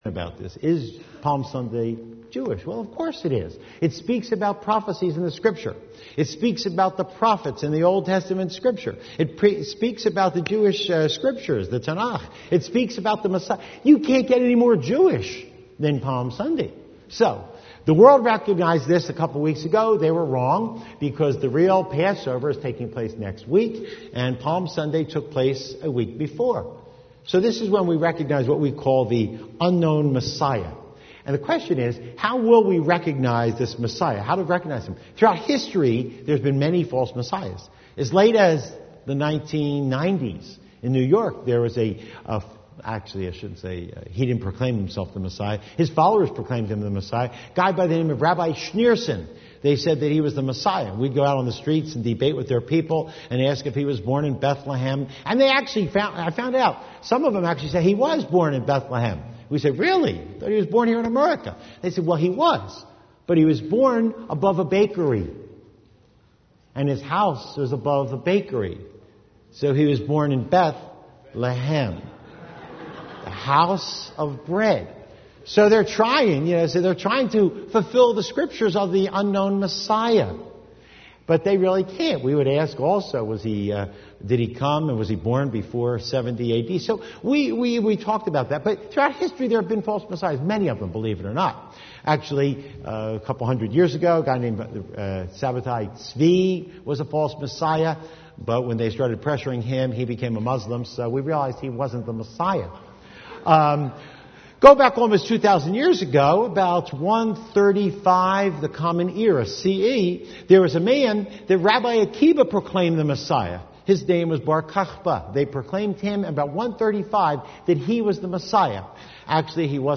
Shuvah Yisrael | Sermons 2008